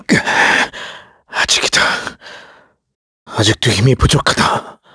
KaselB-Vox_Dead_kr.wav